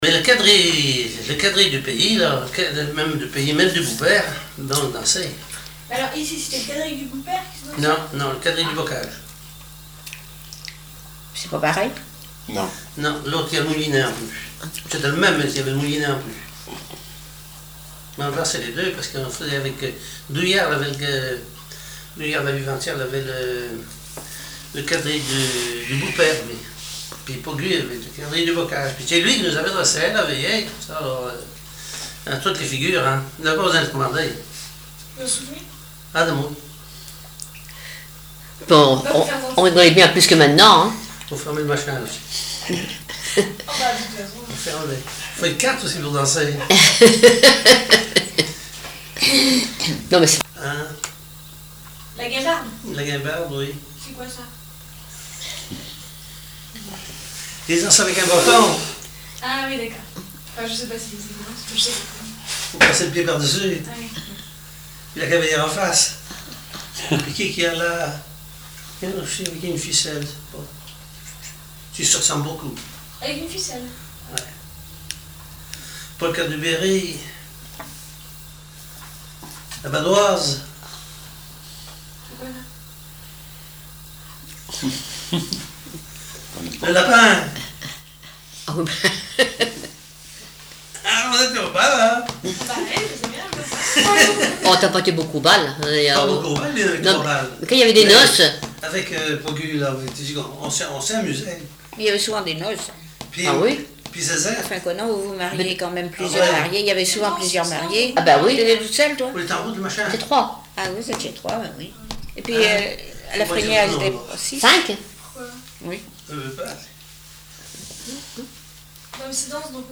témoignage sur les noces